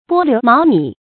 波流茅靡 bō liú máo mǐ 成语解释 随波逐流，随风而倒。
ㄅㄛ ㄌㄧㄨˊ ㄇㄠˊ ㄇㄧˇ